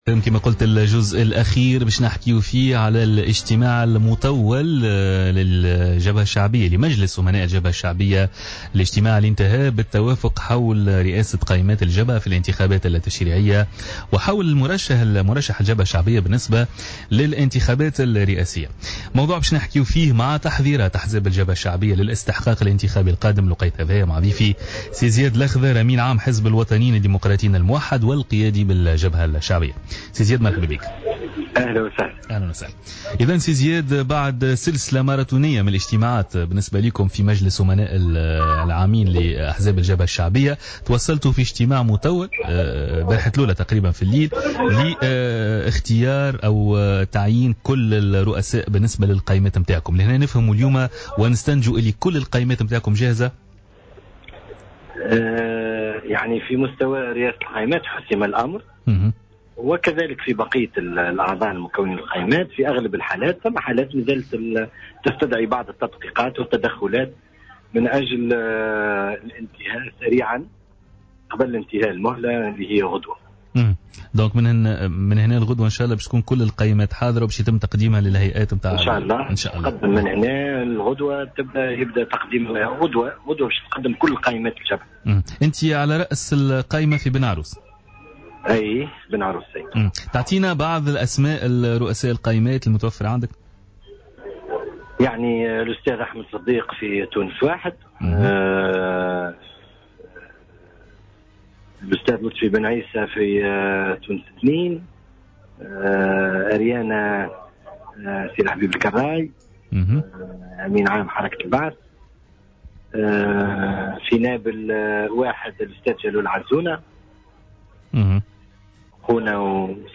Zied Lakhdher, secrétaire général du parti Watad et leader au front populaire a indiqué ce mercredi 27 août 2014 dans une intervention sur les ondes de Jawhara FM, que Hamma Hammami est le candidat officiel du front pour les présidentielles.